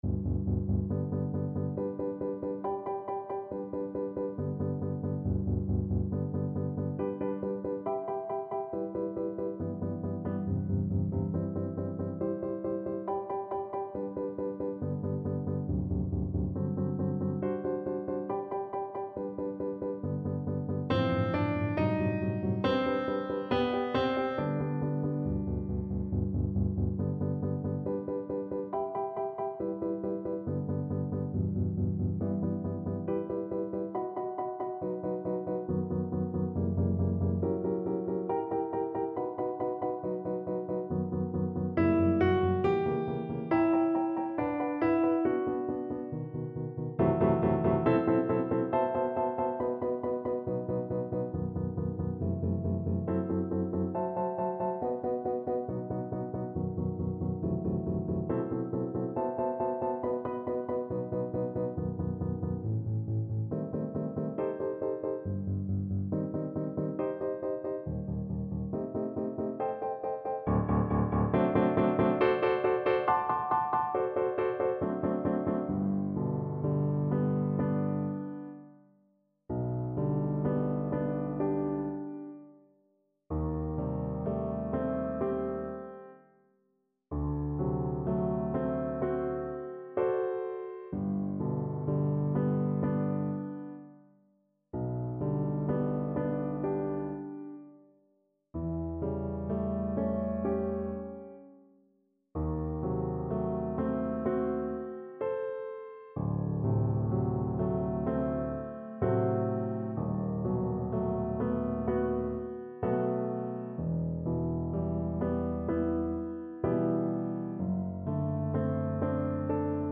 Viola version
3/4 (View more 3/4 Music)
Viola  (View more Intermediate Viola Music)
Classical (View more Classical Viola Music)